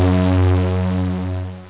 BUZZ_OUT.WAV